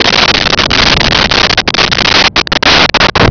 Sfx Thunder 08
sfx_thunder_08.wav